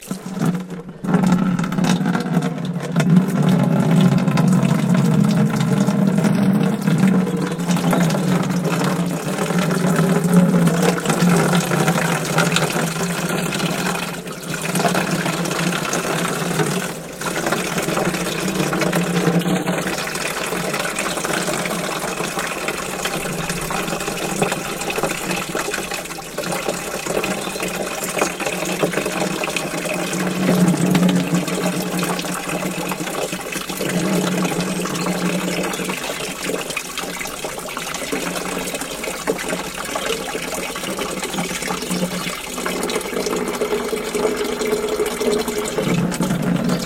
Звуки лейки
Шум наполнения лейки водой